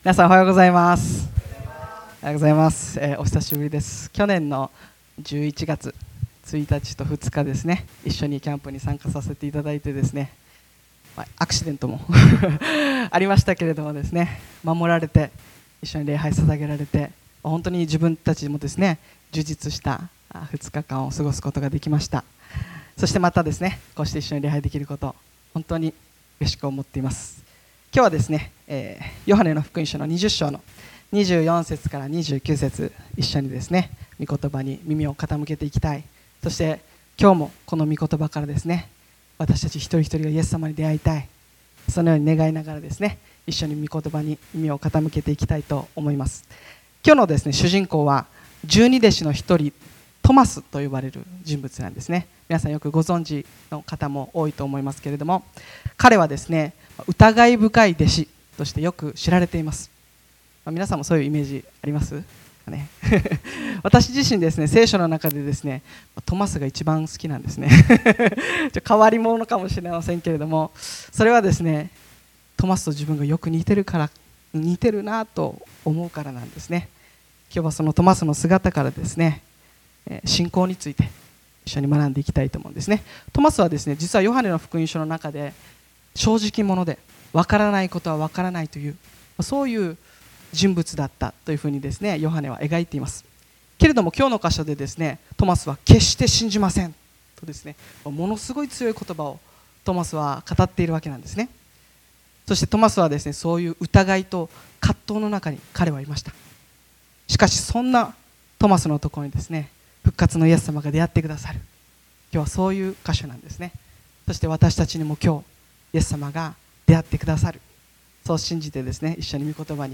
2026年1月25日礼拝メッセージ